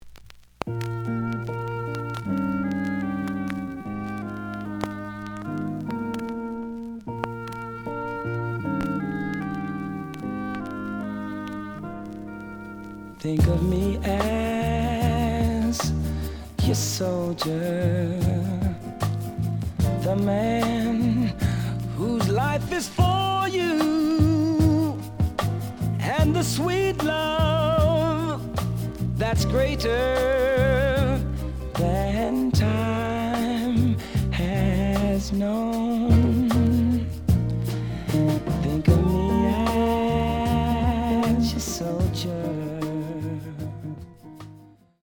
●Genre: Soul, 70's Soul